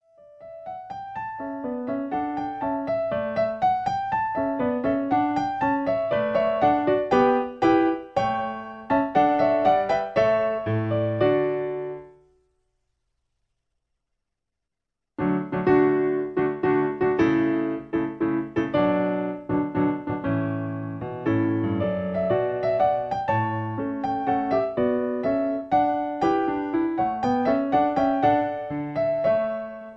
Original Key (D). Piano Accompaniment